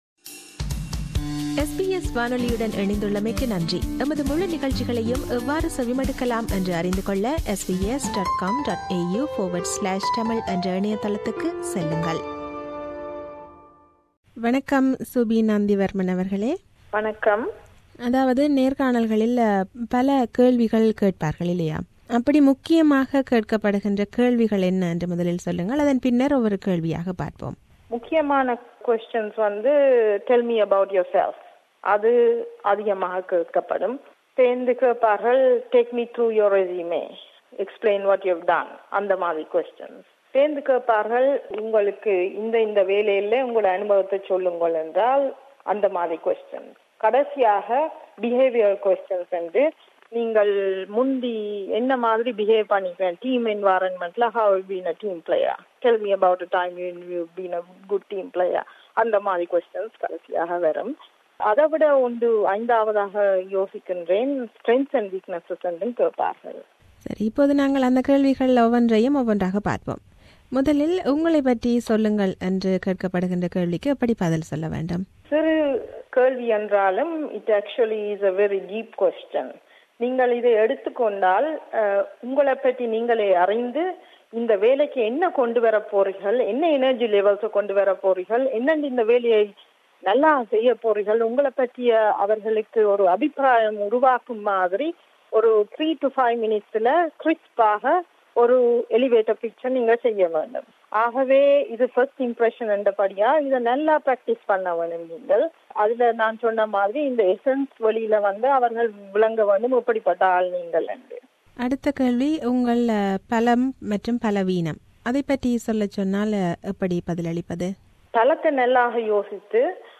This interview provides tips and advice on preparing for and attending interviews: